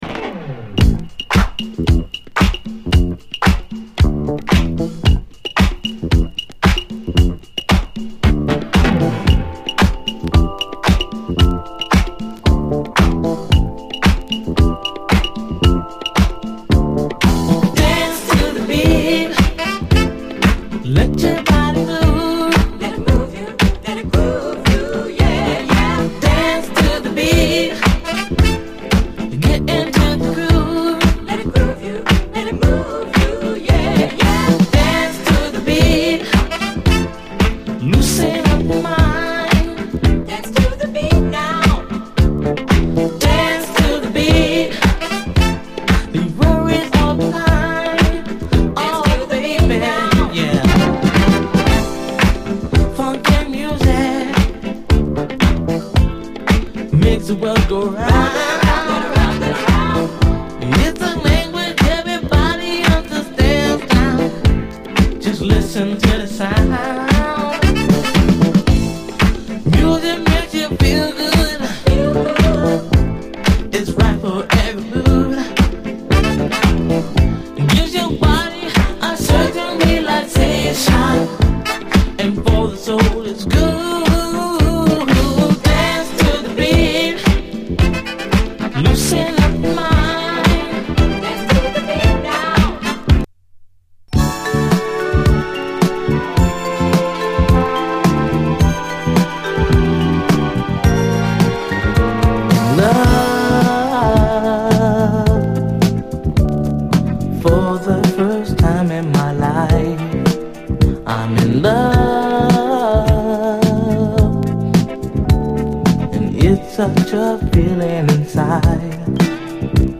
SOUL, 70's～ SOUL, DISCO
若き80’Sソウル・シンガー！小粋なグレイト・モダン・ソウル
ビューティフル・メロウ・ソウル